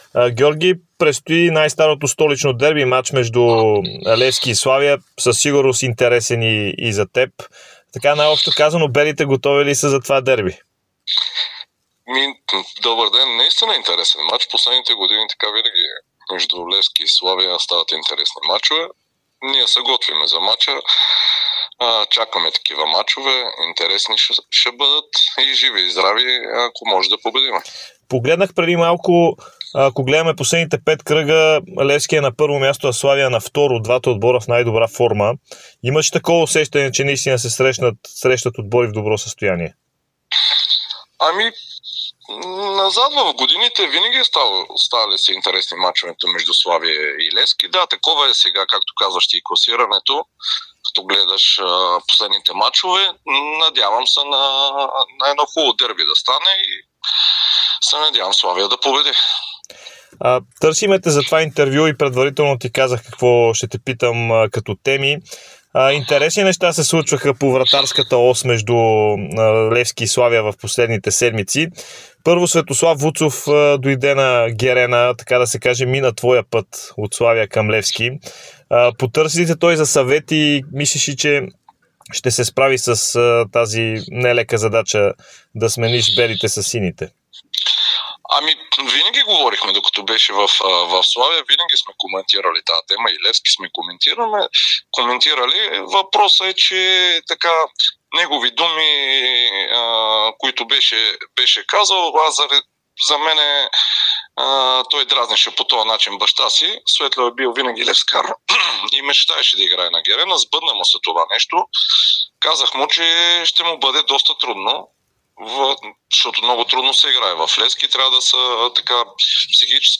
Вратарят на Славия и бивш такъв на Левски Георги Петков даде специално интервю за Дарик радио и dsport преди дербито между двата отбора от поредния кръг на efbet Лига. Той коментира преминаването на Светослав Вуцов на „Георги Аспарухов“, както и ситуацията с вратарите в Славия и българския футбол.